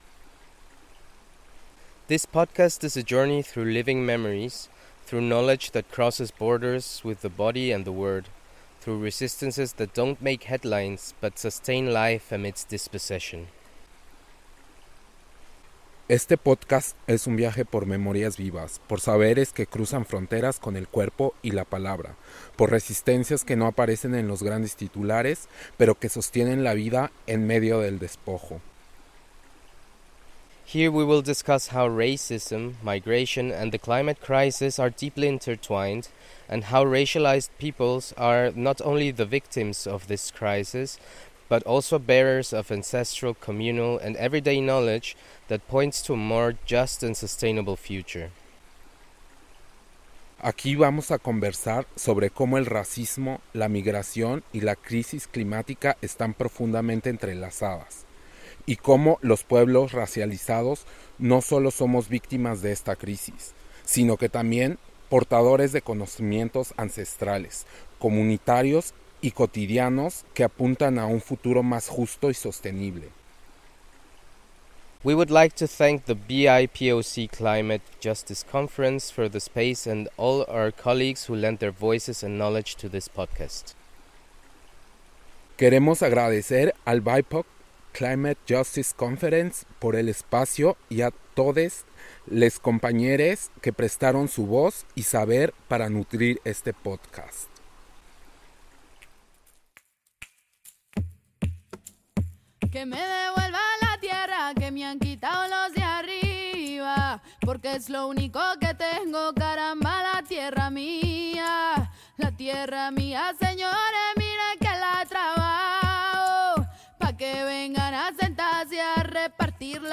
Created during the BIPoC Climate Justice Summit 2025 in Ziordia, this collective audio collage serves as a living sound archive of our voices, memories, and visions for ecological justice.
Through the workshops The resulting piece weaves together these diverse sonic fragments into a shared narrative — amplifying decolonial perspectives on climate, justice, and community.